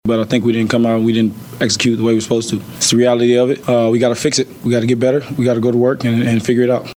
Smith reflected on the team’s loss.